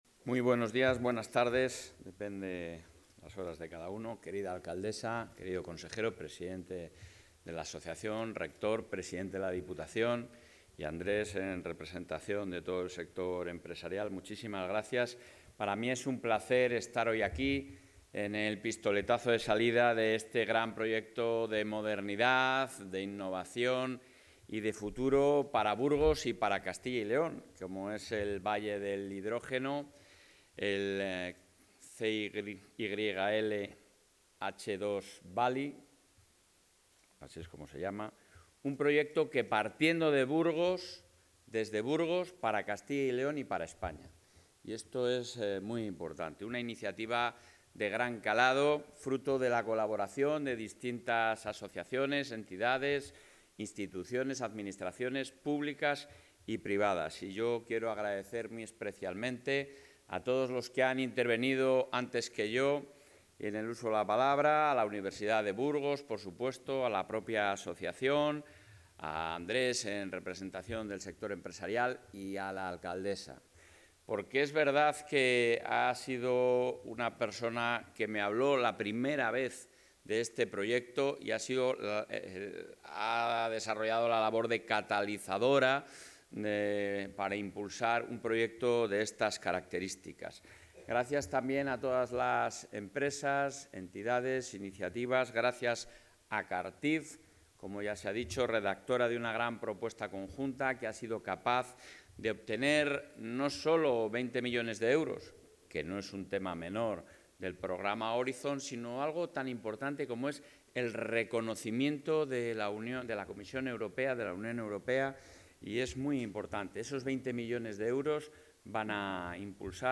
Intervención del presidente de la Junta.
El presidente de la Junta de Castilla y León, Alfonso Fernández Mañueco, ha participado hoy en el acto de presentación del Valle del Hidrógeno, 'CyLH2Valley', un proyecto estratégico que convierte a Burgos en motor de transformación energética e industrial.